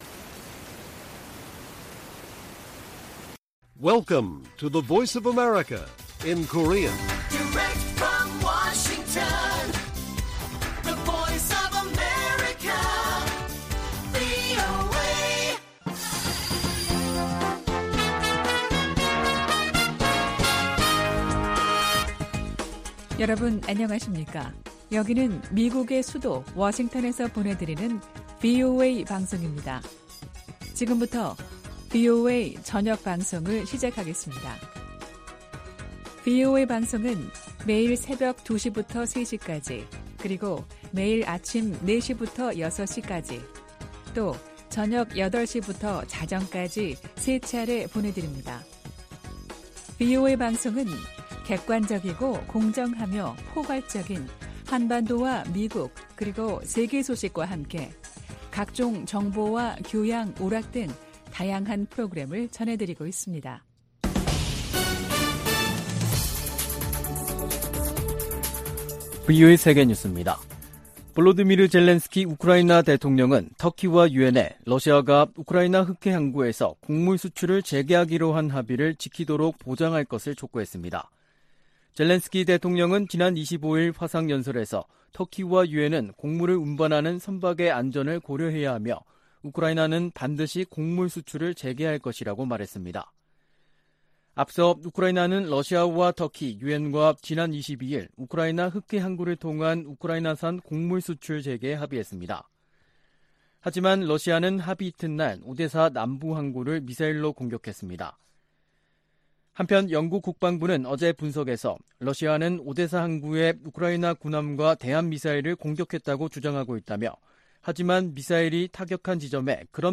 VOA 한국어 간판 뉴스 프로그램 '뉴스 투데이', 2022년 7월 27일 1부 방송입니다. 미국 정부가 북한의 추가 핵실험이 한반도의 불안정성을 가중시킬 것이라며 동맹과 적절히 대응할 것이라고 밝혔습니다. 박진 한국 외교부 장관이 북한이 7차 핵실험을 감행하면 더 강력한 국제사회 제재에 직면할 것이라고 경고했습니다. 미국의 비확산 담당 고위관리가 핵확산금지조약 평가회의를 계기로 국제사회가 북한의 추가 핵실험과 미사일 시험을 규탄하기 기대한다고 밝혔습니다.